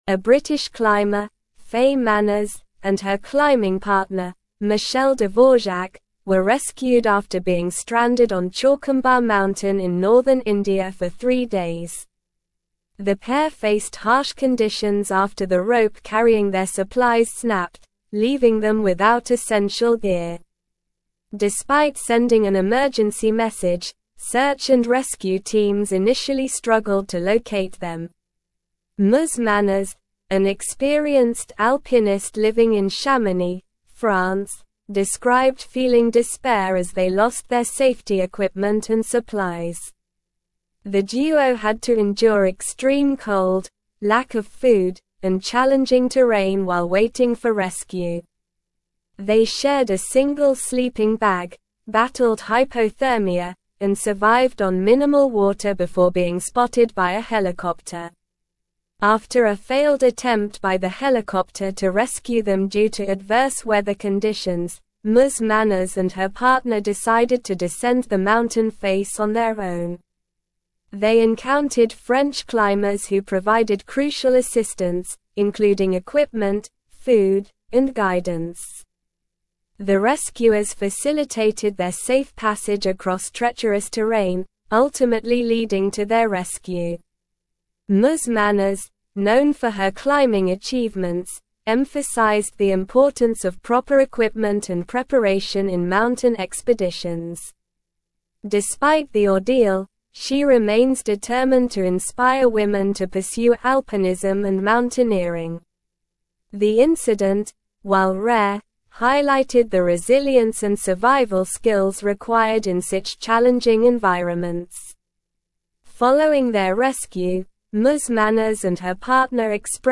Slow
English-Newsroom-Advanced-SLOW-Reading-Resilience-and-Survival-Climbers-Ordeal-on-Himalayan-Mountain.mp3